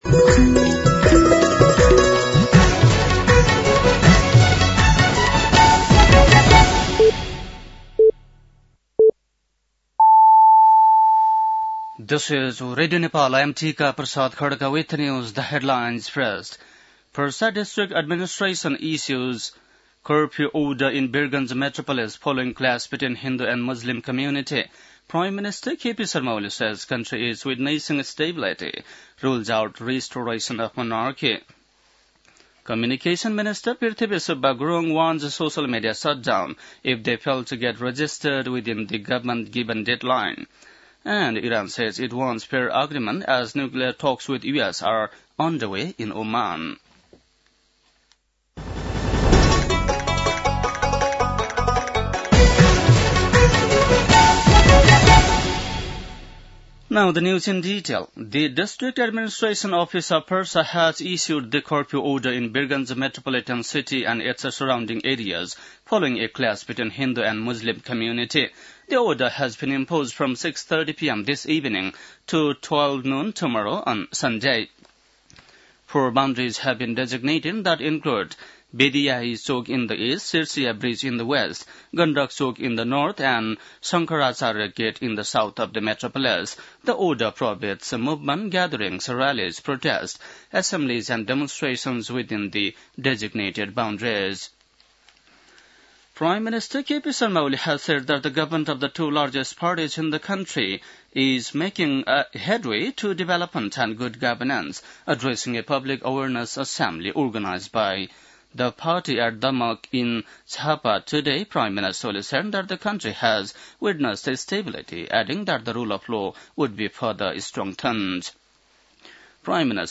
बेलुकी ८ बजेको अङ्ग्रेजी समाचार : ३० चैत , २०८१